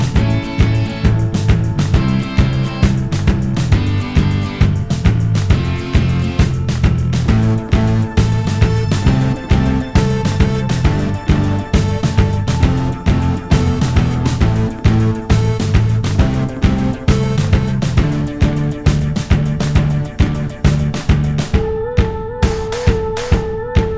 no Backing Vocals Indie / Alternative 4:13 Buy £1.50